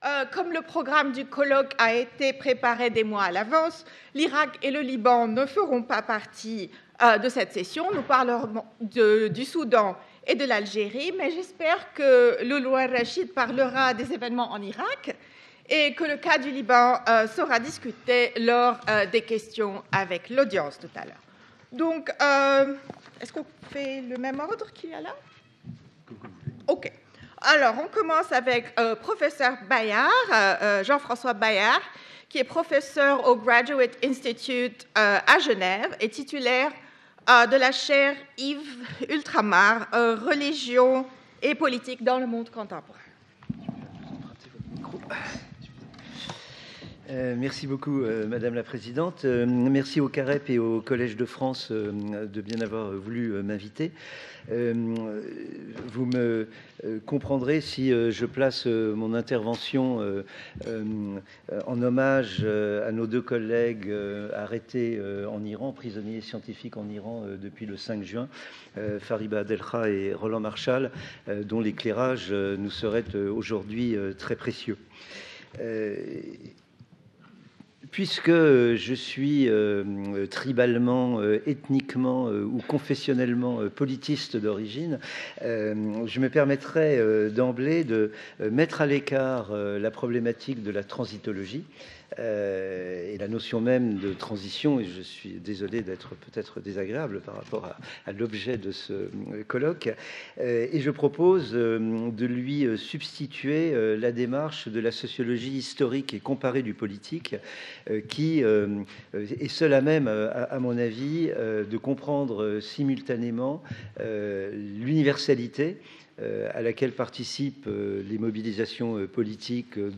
This second round table looks at the Arab world as a laboratory for developing and negotiating new forms of relationship with politics. The debate will explore the renewal of repertoires of collective and political action (mobilization processes, the role of parties, reconfiguration of modes of engagement through the use of digital tools, widening the scope of commitment, etc.).